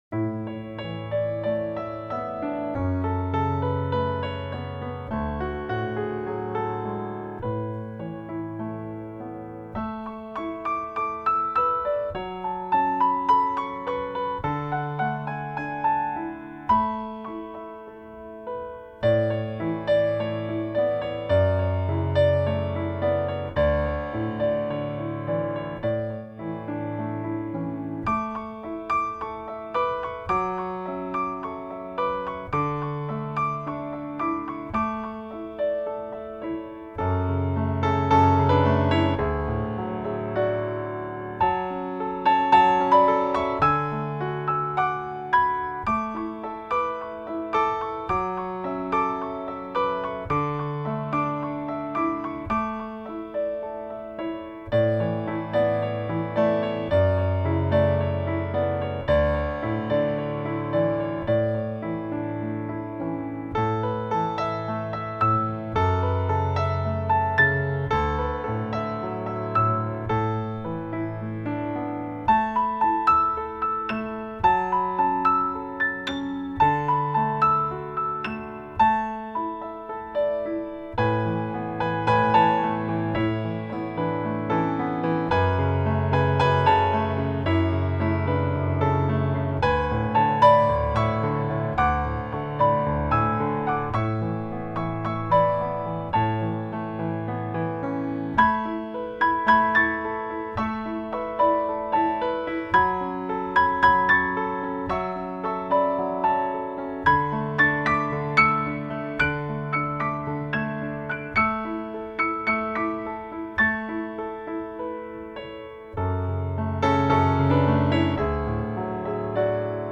新世纪
★轻盈的触键犹如徐暖的微风
享受城市晨光初现时的片刻宁静
即使在乔治温斯顿的音乐中，也难有这般清透的音色
没有多余的音符，所有的乐句一起组成令人满意的结构。